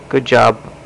Good Job Sound Effect
Download a high-quality good job sound effect.
good-job-1.mp3